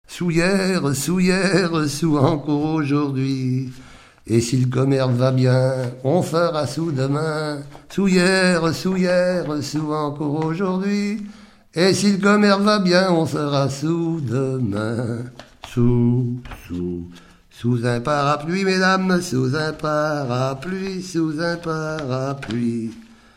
circonstance : bachique
Genre brève
Pièce musicale inédite